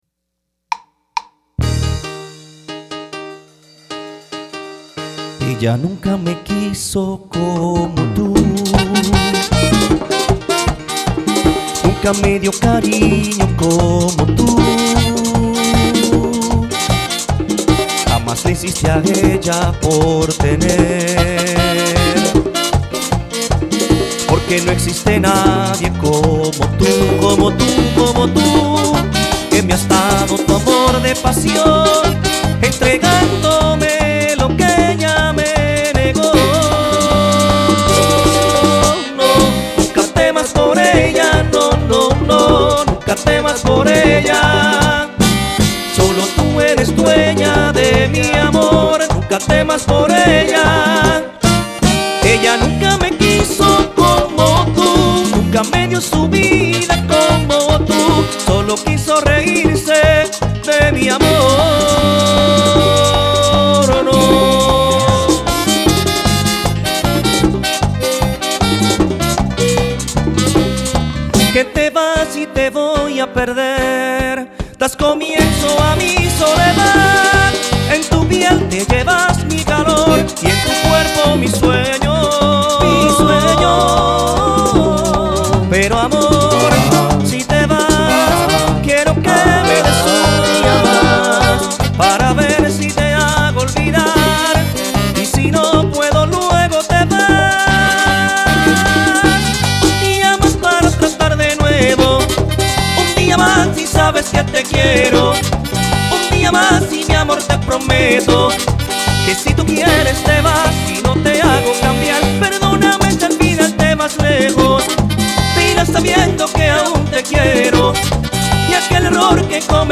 El destacado merenguero típico
en una versión con los colores y matices modernos
merengues típicos